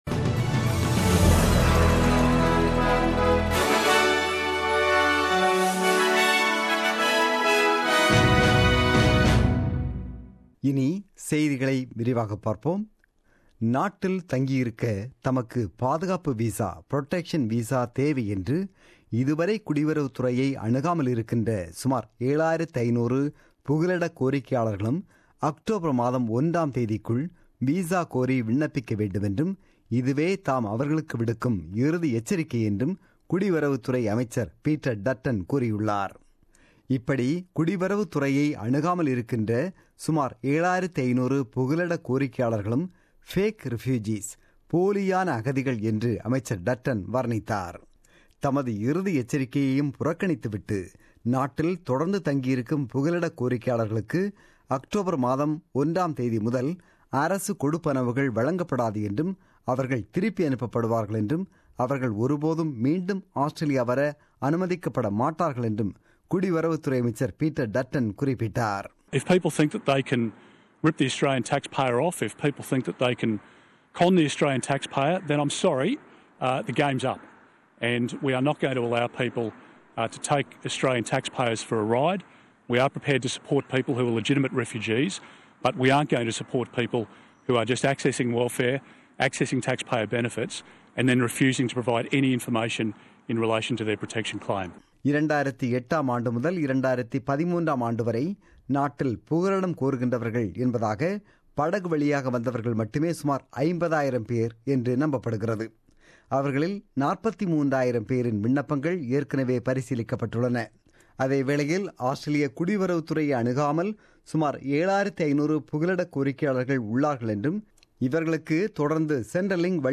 The news bulletin broadcasted on 21 May 2017 at 8pm.